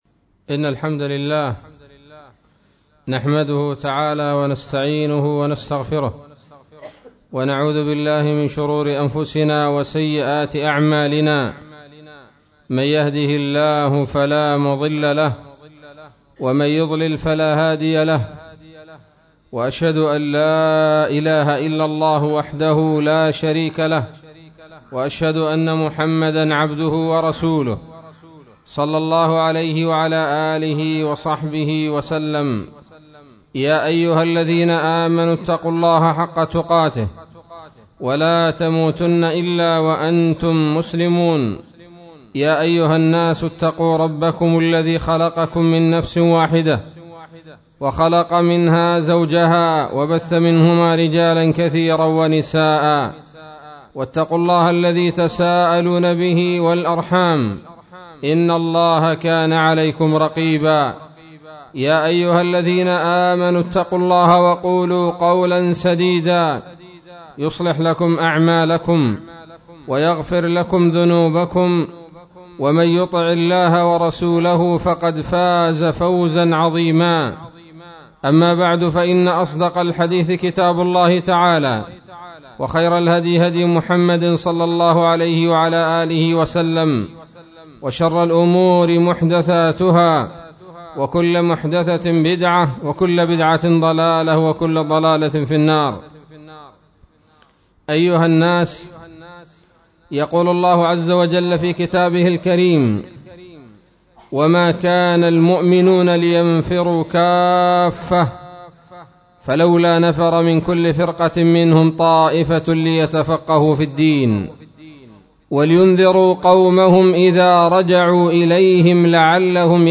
خطبة-جمعة-عن-العلم-8-صفر-1437هـ.mp3